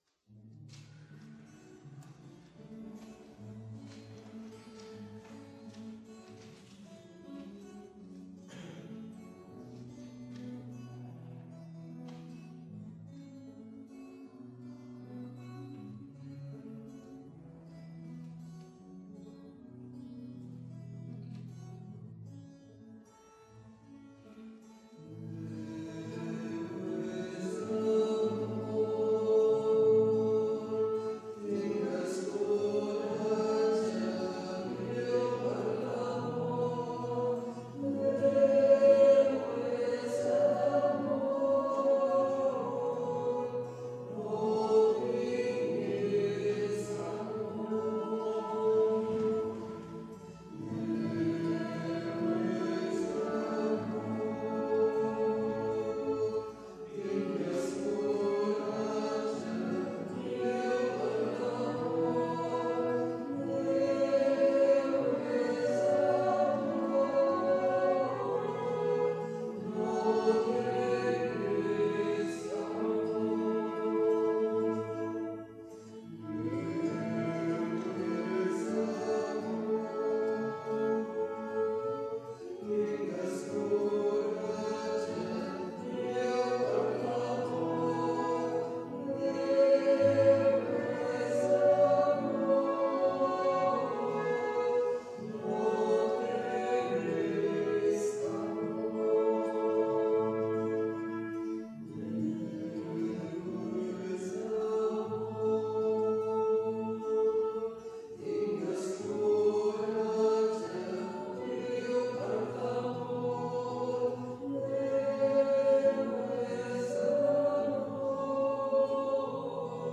Església de Santa Anna - Diumenge 29 d'octubre de 2017
Vàrem cantar...